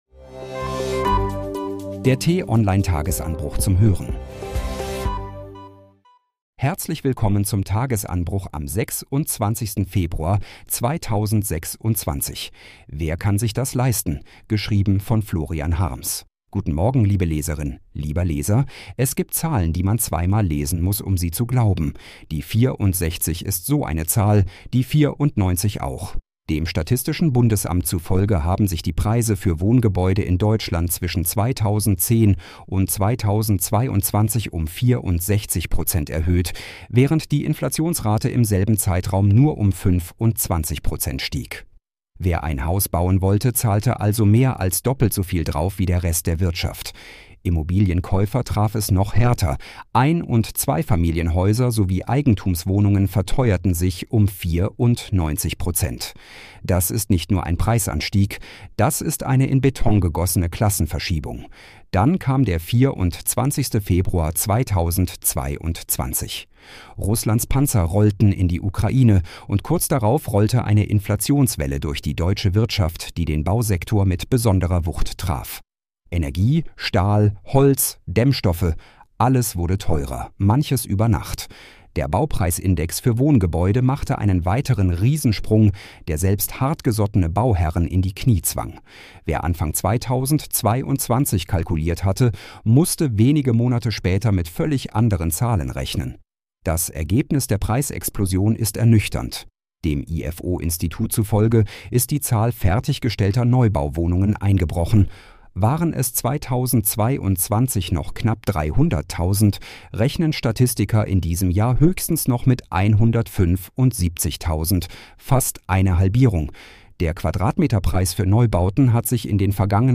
Den „Tagesanbruch“-Podcast gibt es immer montags bis freitags ab 6 Uhr zum Start in den Tag vorgelesen von einer freundlichen KI-Stimme – am Wochenende mit einer tiefgründigeren Diskussion.